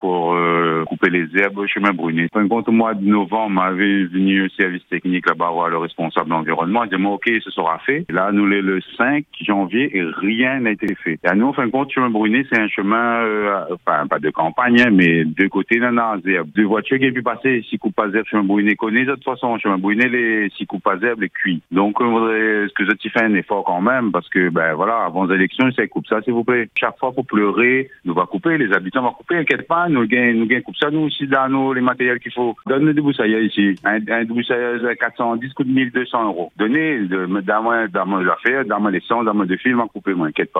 Un habitant du chemin Brunet à Saint-André alerte sur l’état de la voirie.